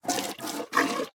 minecraft / sounds / entity / cow / milk1.ogg
milk1.ogg